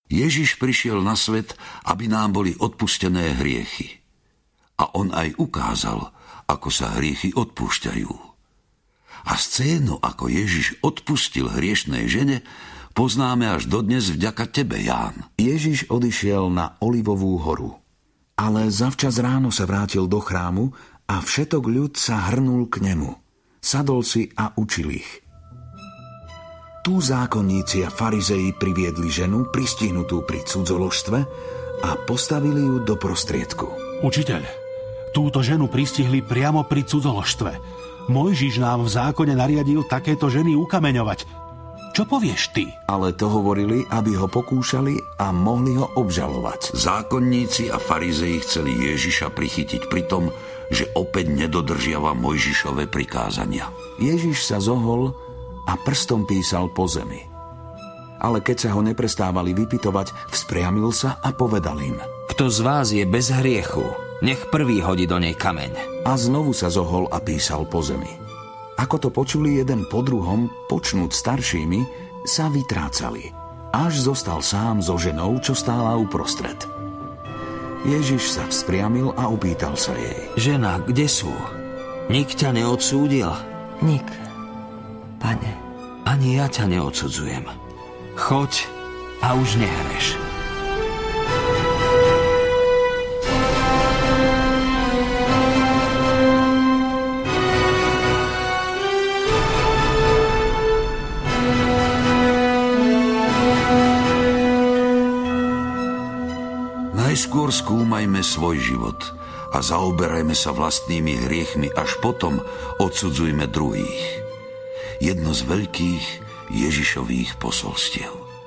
Biblia - Život Ježiša 3 audiokniha
Biblia - Život Ježiša 3 - dramatizované spracovanie Biblie podľa Nového zákona.
Ukázka z knihy